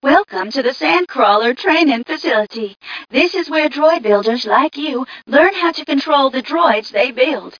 1 channel
mission_voice_tgca001.mp3